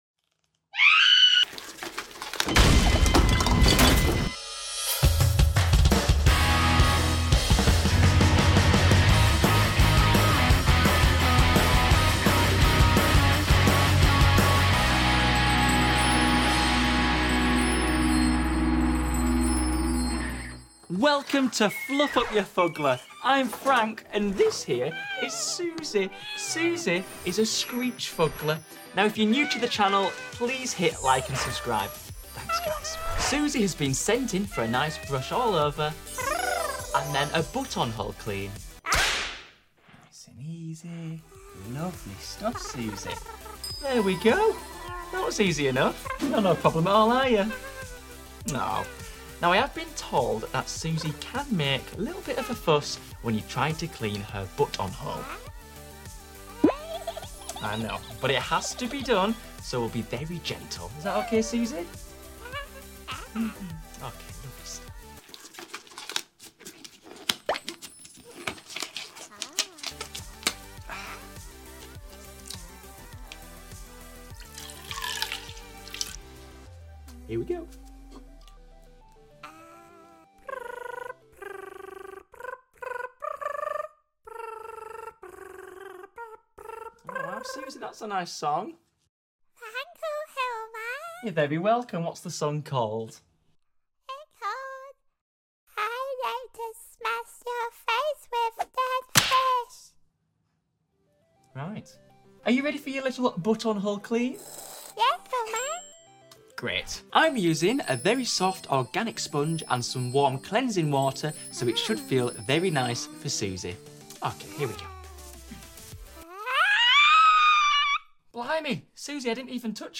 Fluffing up your Fuggler is sound effects free download By fugglers 43 Downloads 2 days ago 236 seconds fugglers Sound Effects About Fluffing up your Fuggler is Mp3 Sound Effect Fluffing up your Fuggler is never easy, especially Susie Screech! 😱 Comment with a 🗣 if you think you could withstand her ear-piercing screams long enough to get her clean?